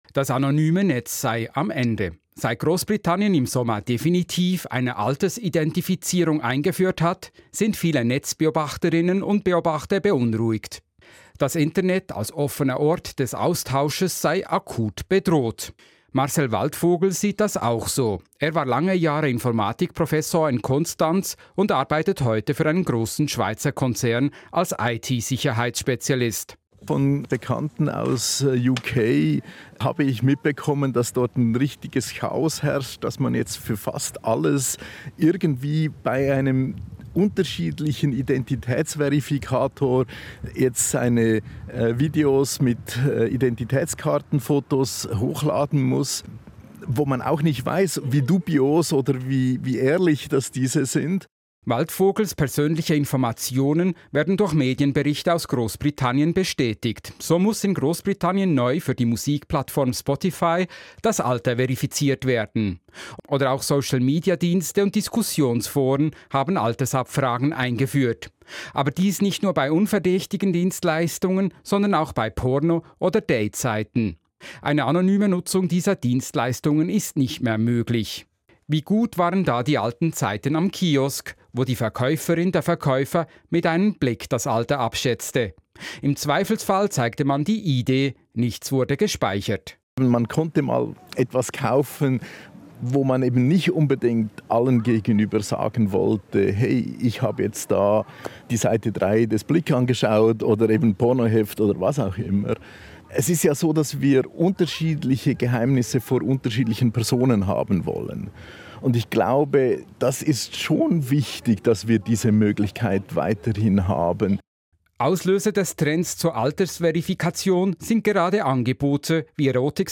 Die e-ID ist aktuell auch im rund um Altersverifikation in Diskussion. In einem Interview mit Radio SRF versuchte ich, einige Punkte zu klären.